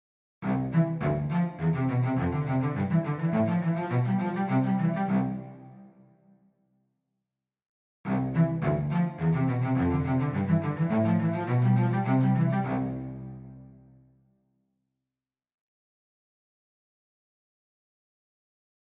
low-G and again low-C (last note).